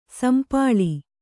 ♪ sampāḷi